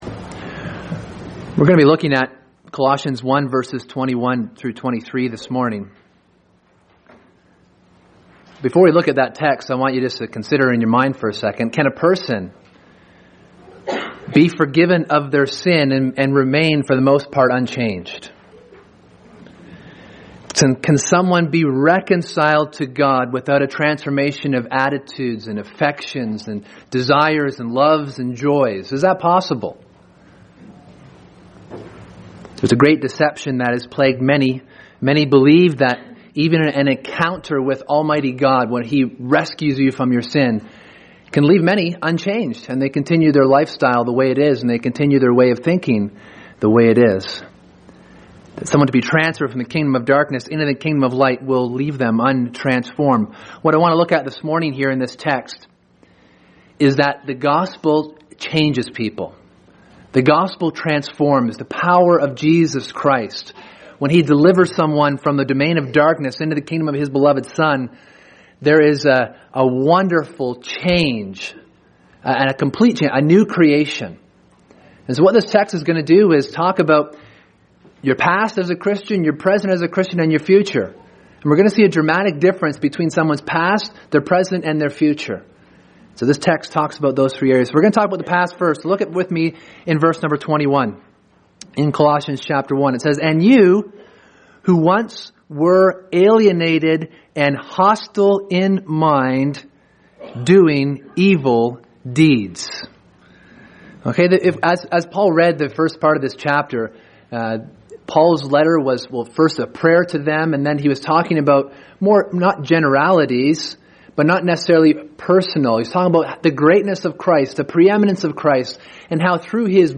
Sermon: Past, Present, Future: How the Gospel Changes Everything